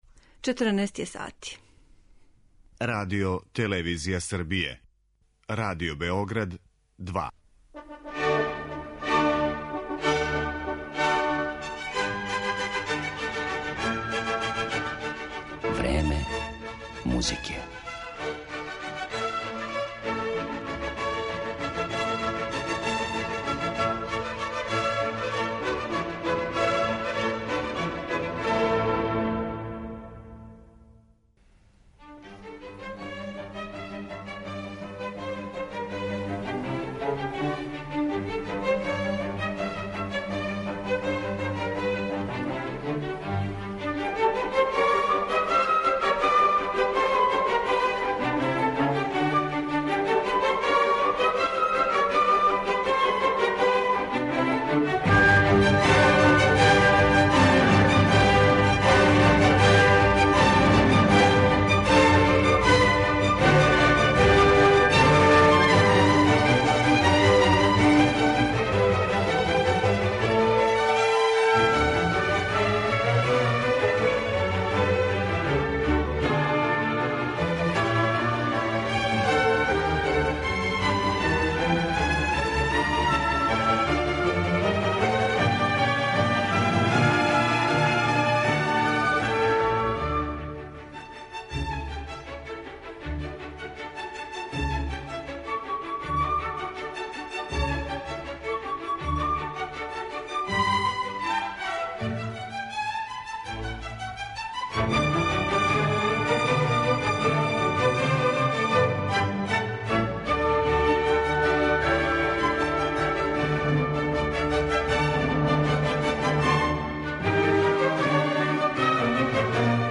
Слушаћете дела из опуса Хајдна, Чајковског, Вагнера, Бартока и Мусоргског.